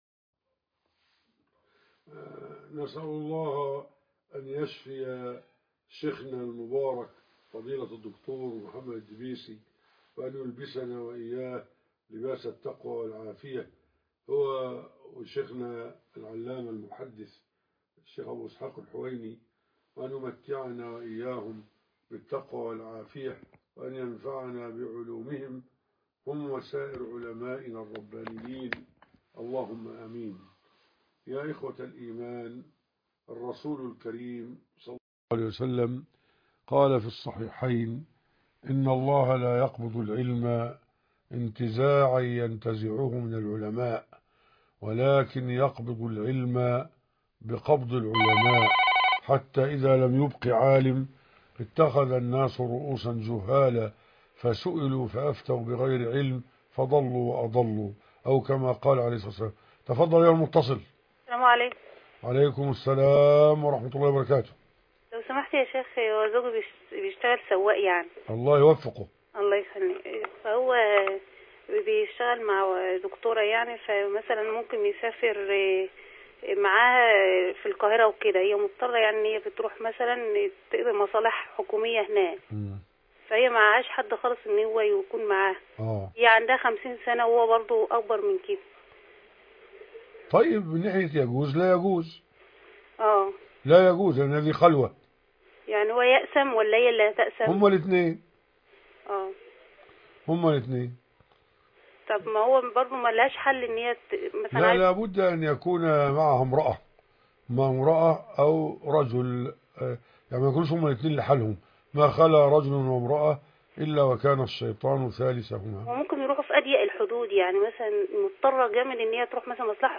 لقاء الفتاوى